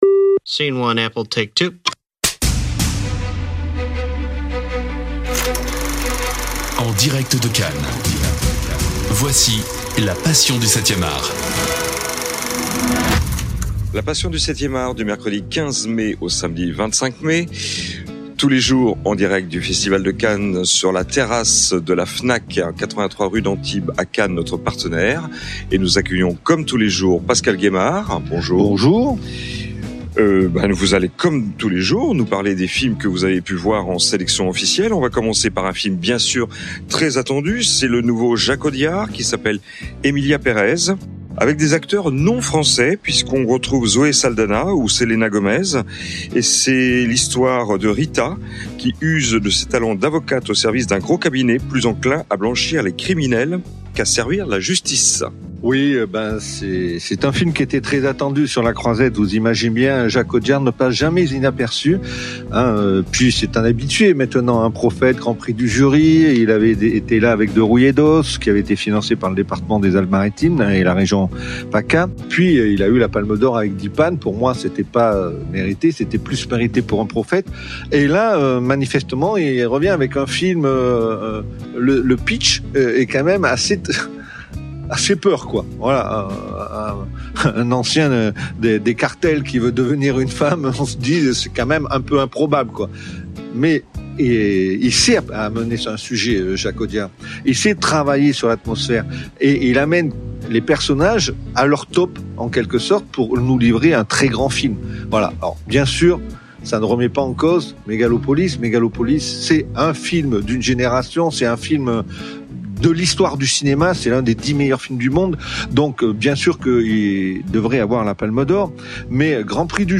En direct de Cannes